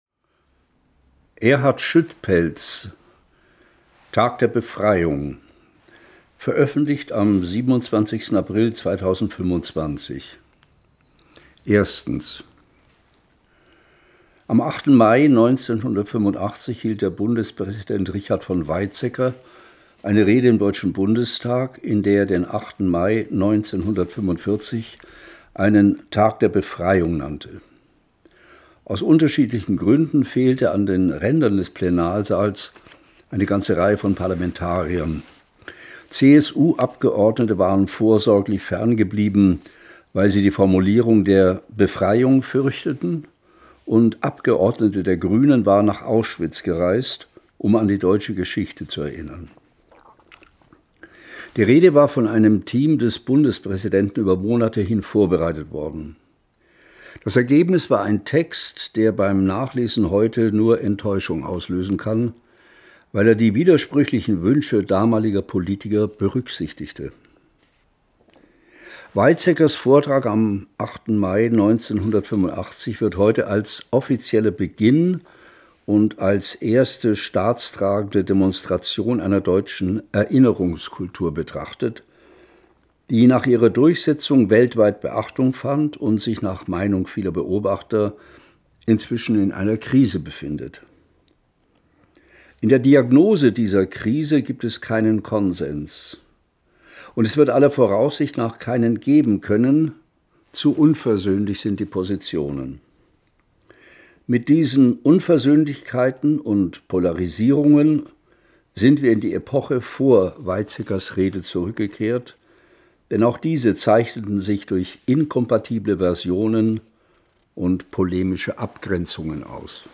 gelesen von Hanns Zischler
Hörfassung